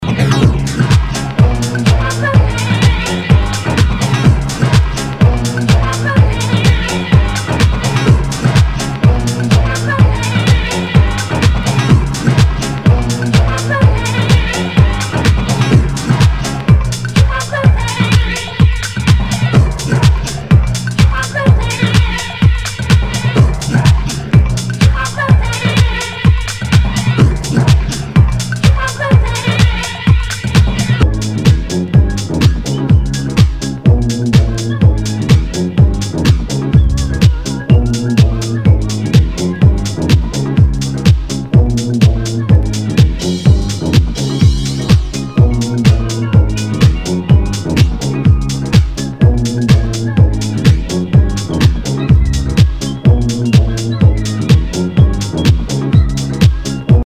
HOUSE/TECHNO/ELECTRO
ナイス！ディスコ・ハウス！
[VG ] 平均的中古盤。スレ、キズ少々あり（ストレスに感じない程度のノイズが入ることも有り）